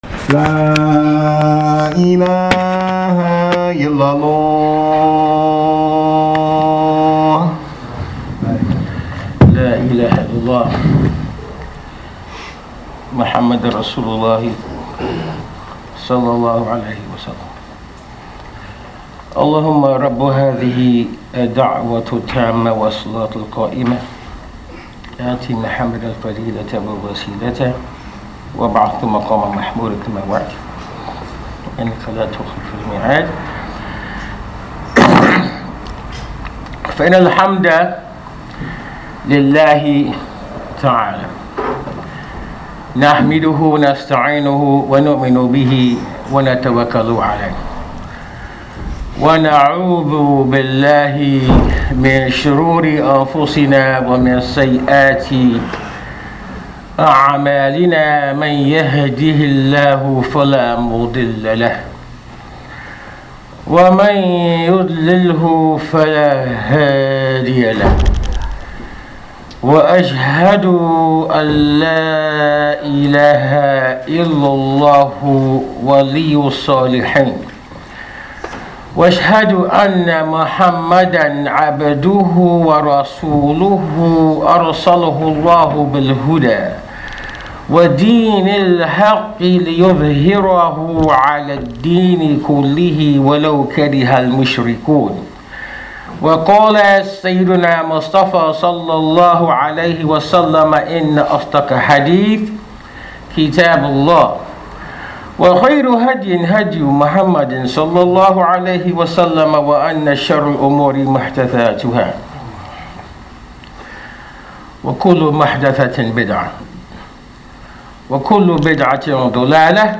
recorded at Masjid Ibrahim Islamic Center, Sacramento California.